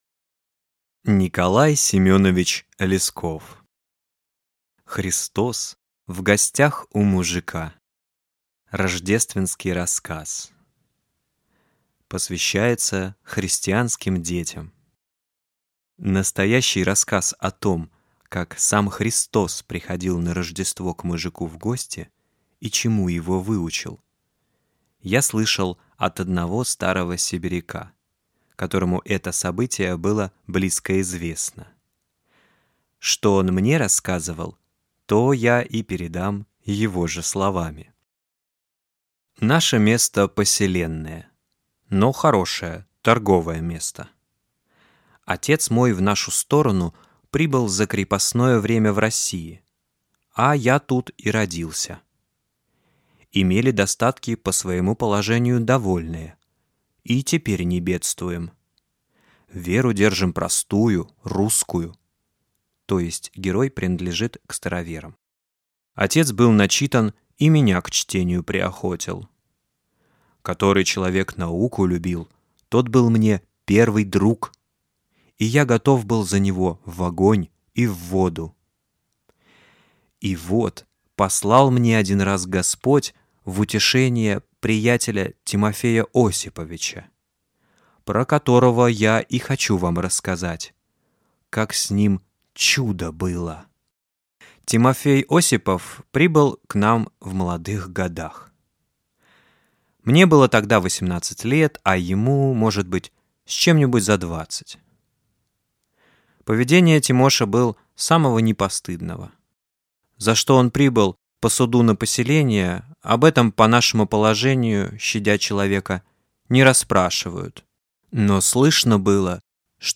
Аудиокнига Христос в гостях у мужика | Библиотека аудиокниг